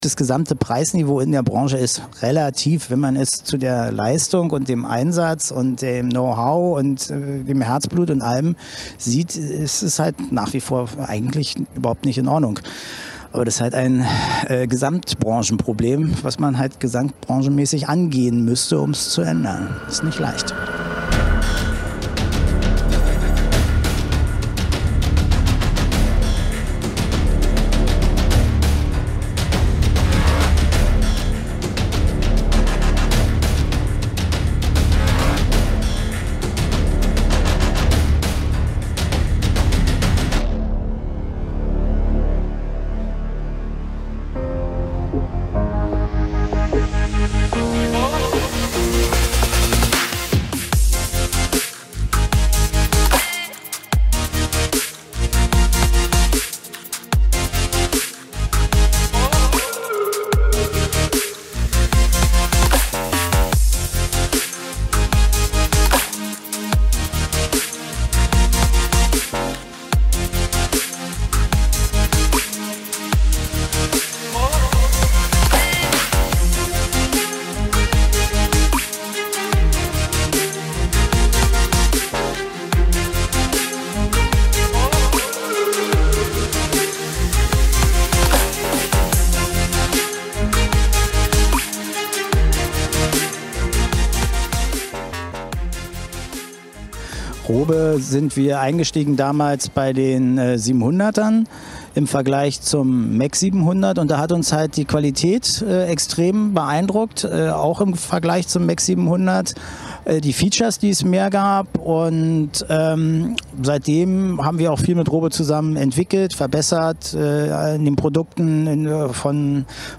Interview
Gedreht wurde das Video am Rande des Melt! Festival 2019 in Ferropolis, der Stadt aus Eisen.